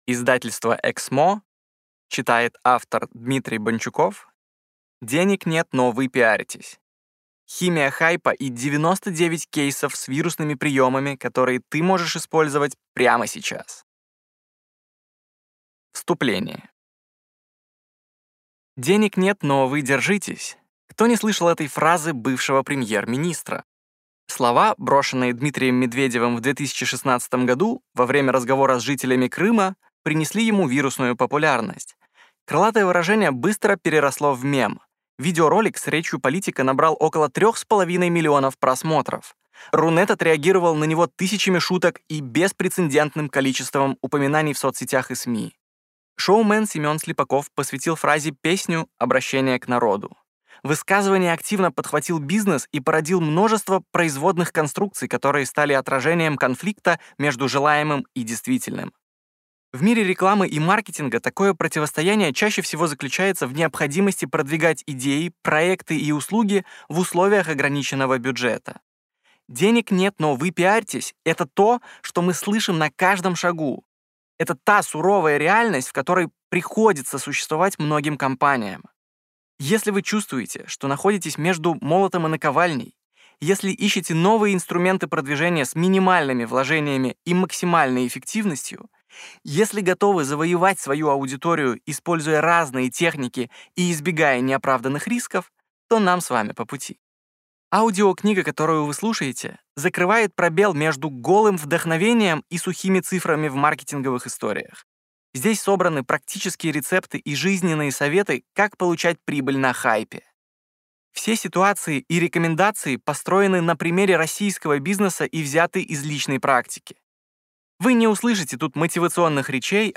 Аудиокнига Денег нет, но вы пиарьтесь! Химия хайпа и 99 кейсов с вирусными приемами | Библиотека аудиокниг